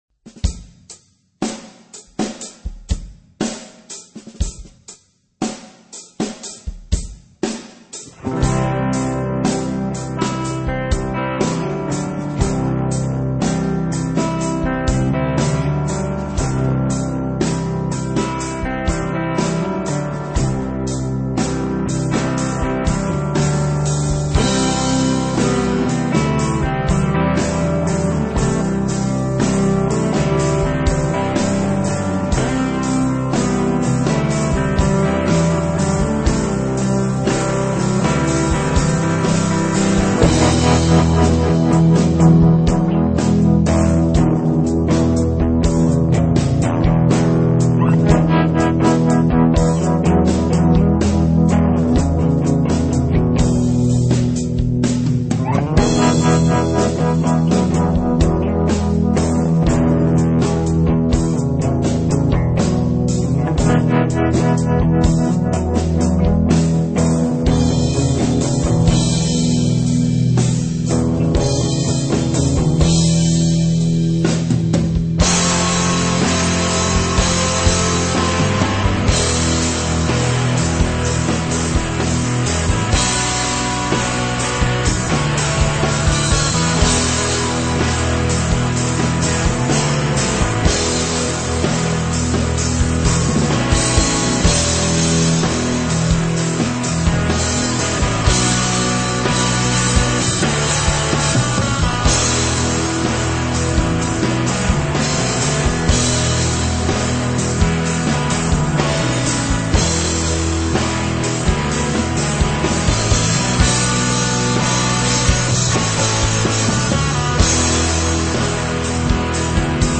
rock
metal
punk
hard rock
high energy rock and roll